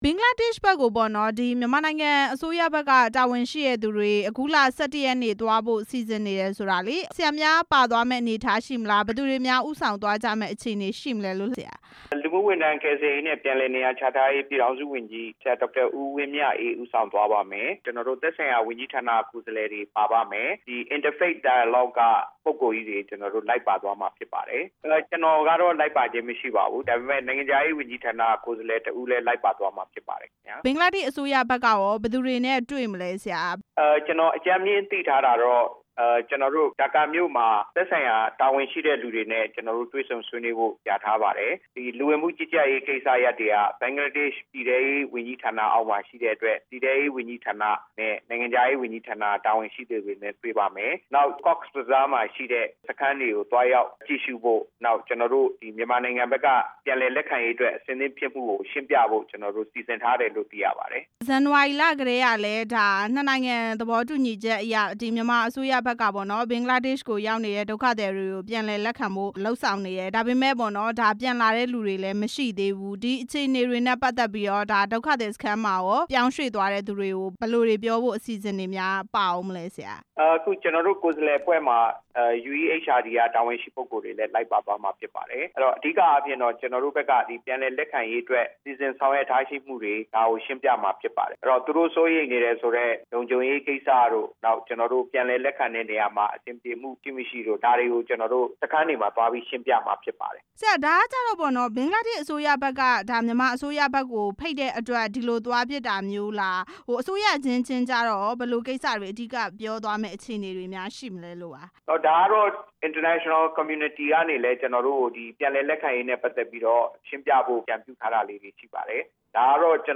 ဒုက္ခသည်လက်ခံရေး နိုင်ငံခြားရေးဝန်ကြီးဌာန အမြဲတမ်းအတွင်းဝန်နဲ့ မေးမြန်းချက်